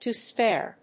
アクセントto spáre